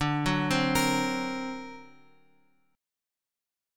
D+7 chord {x 5 8 5 7 6} chord